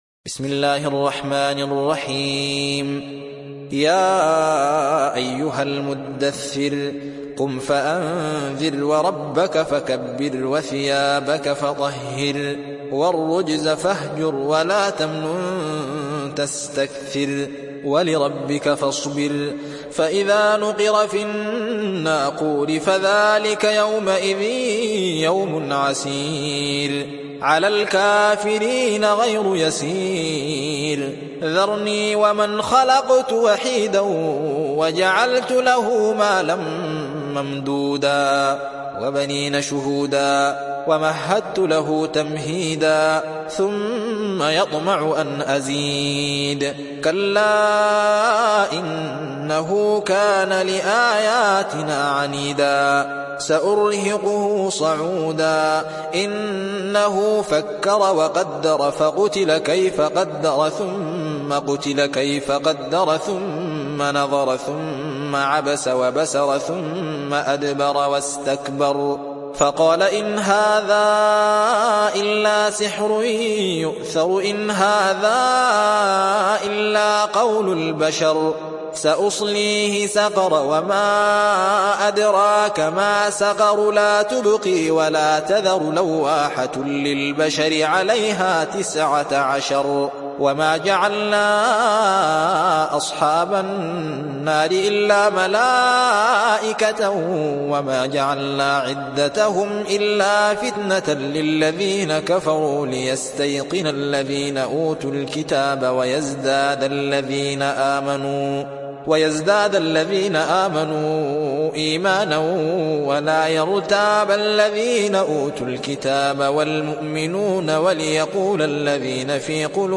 تحميل سورة المدثر mp3 بصوت الزين محمد أحمد برواية حفص عن عاصم, تحميل استماع القرآن الكريم على الجوال mp3 كاملا بروابط مباشرة وسريعة